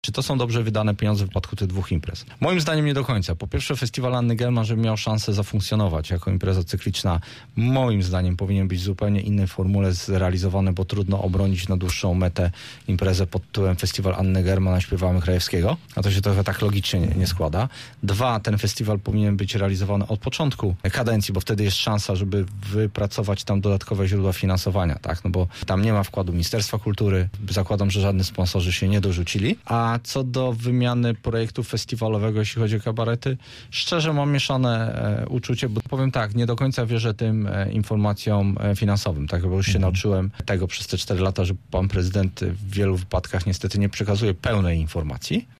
Pieniędzy na działania promocyjne poszło znacznie więcej – były koncerty, otwarcia, a nawet uroczyste odsłonięcie pomnika św. Urbana zostało okraszone pokazem sztucznych ogni – mówił radny Sojuszu Lewicy Demokratycznej w Rozmowie Punkt 9: